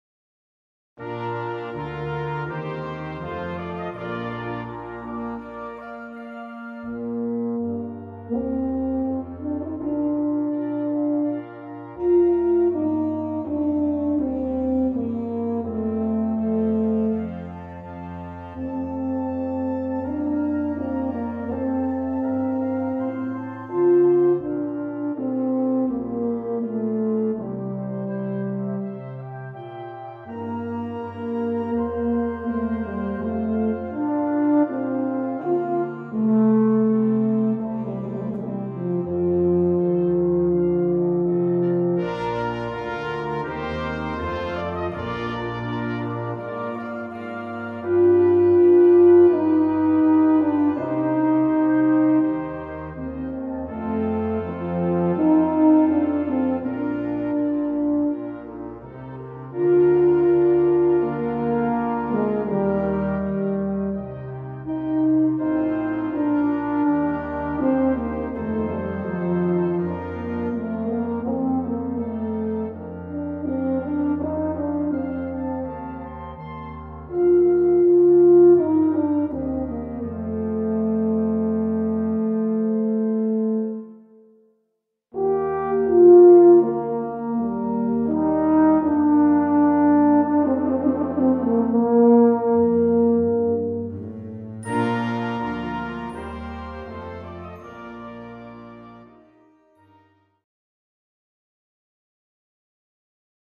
für Solo Euphonium und Blasorchester
Besetzung: Blasorchester